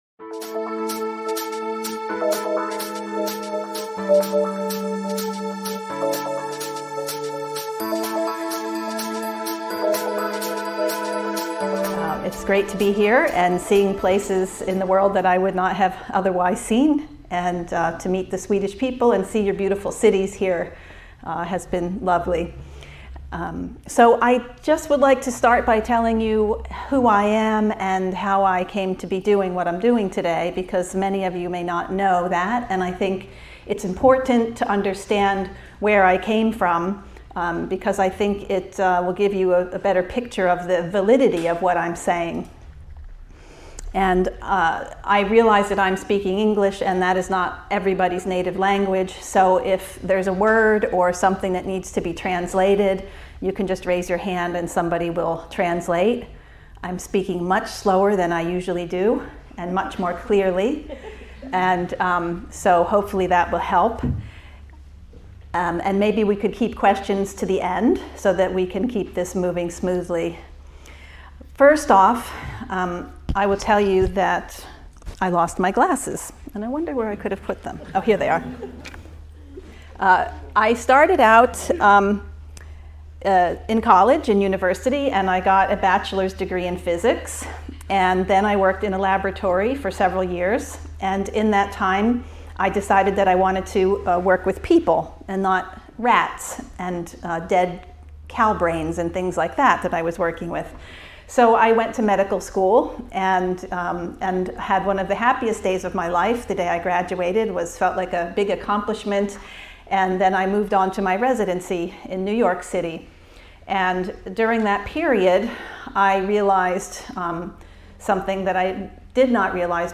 Lecture on vaccines and health FULL PART ONE